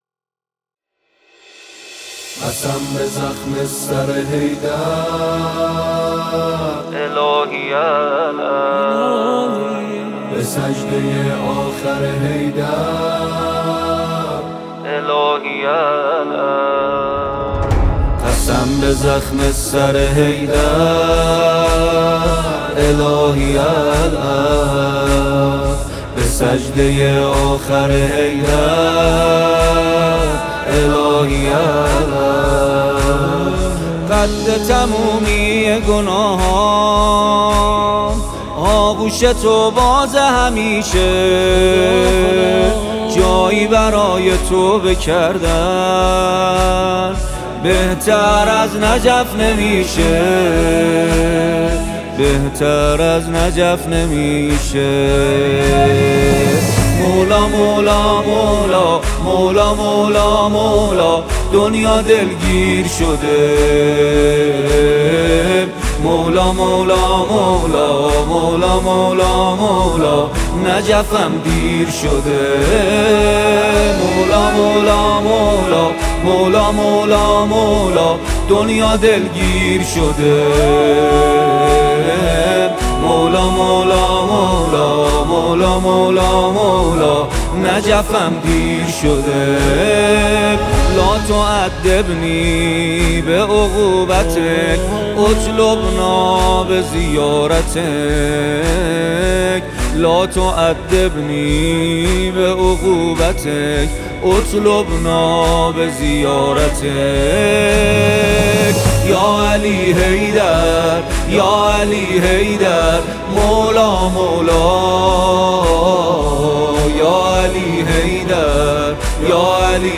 نوحه و مداحی
نسخه استودیویی و جدید مداحی